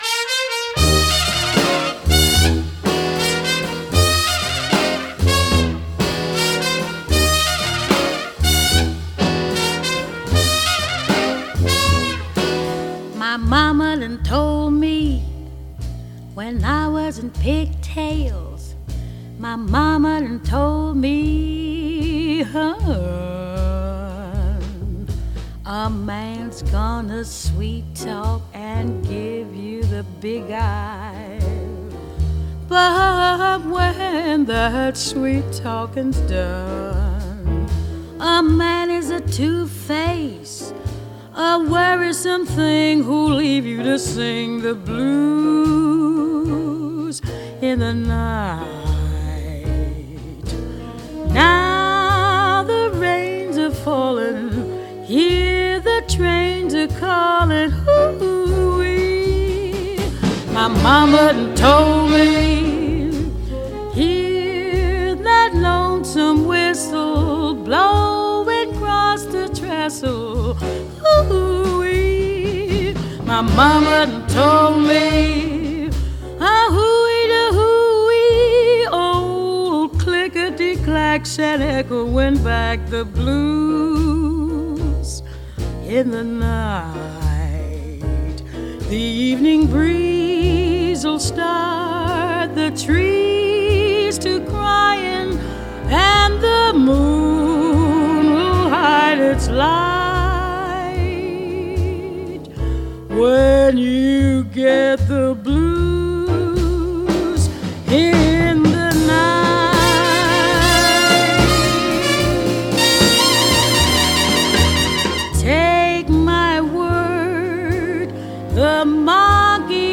Jazz, Moods in blue